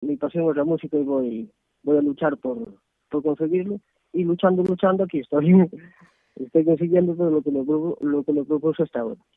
Luchando, luchando... aquí estoy ahora formato MP3 audio(0,18 MB), comenta risueño, al otro lado del hilo telefónico, dedicándonos un alto en el camino de su gira para compartir con nosotros emociones, sentimientos a flor de piel y el deseo, que atisba cada vez más cercano, de alcanzar la gloria del artista con mayúsculas.